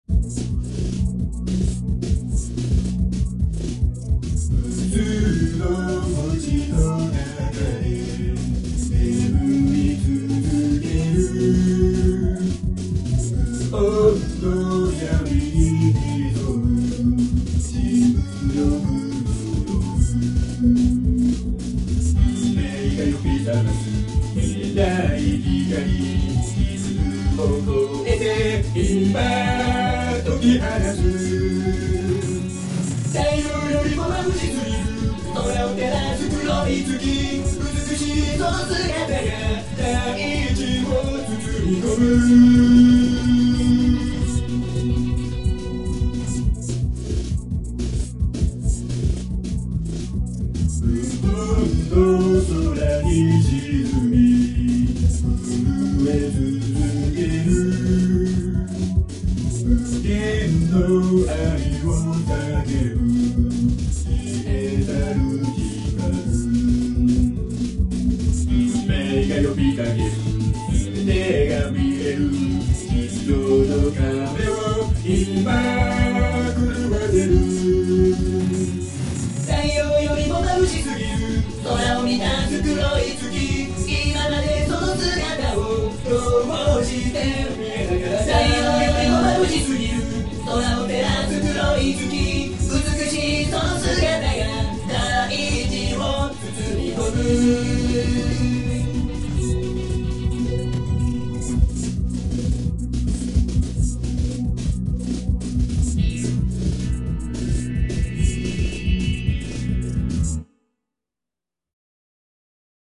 【1.6倍速】